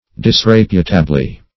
Search Result for " disreputably" : Wordnet 3.0 ADVERB (1) 1. in a disreputable manner ; The Collaborative International Dictionary of English v.0.48: Disreputably \Dis*rep"u*ta*bly\, adv. In a disreputable manner.